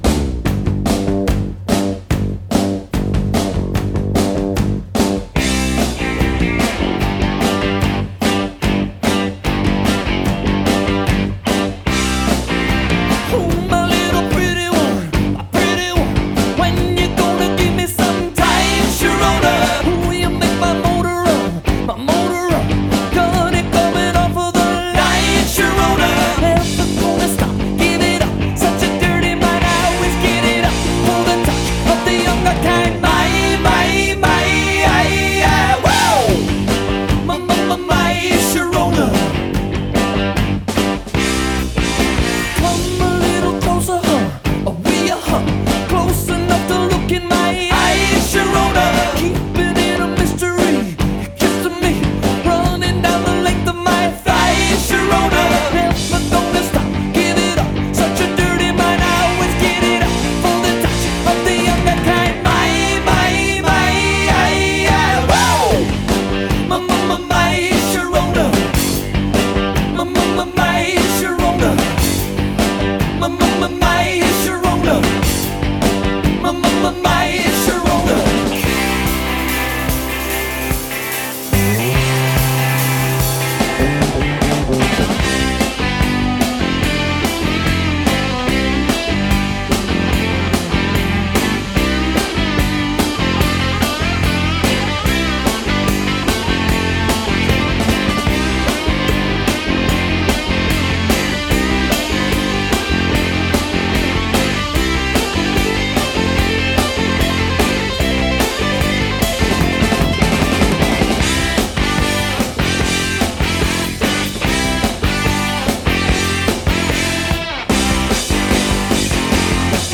BPM143-152
Audio QualityMusic Cut
Obvious BPM drift.